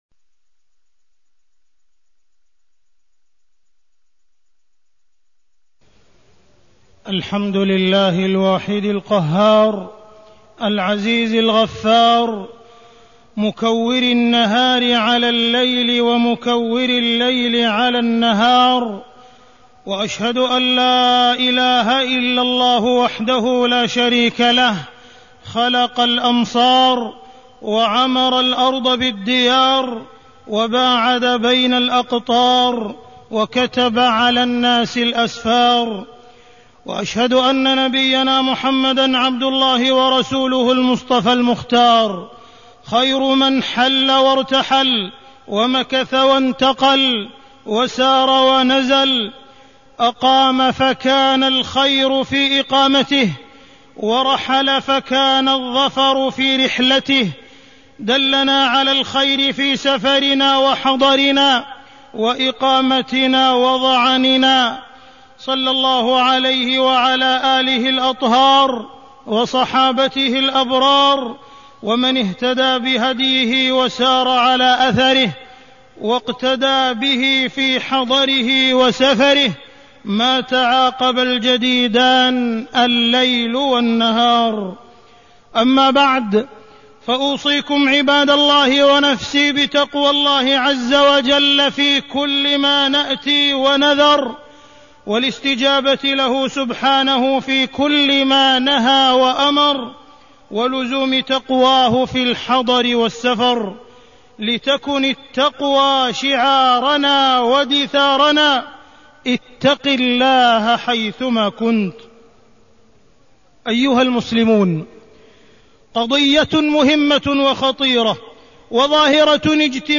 تاريخ النشر ١٠ ربيع الثاني ١٤٢٣ هـ المكان: المسجد الحرام الشيخ: معالي الشيخ أ.د. عبدالرحمن بن عبدالعزيز السديس معالي الشيخ أ.د. عبدالرحمن بن عبدالعزيز السديس الإجازة والسفر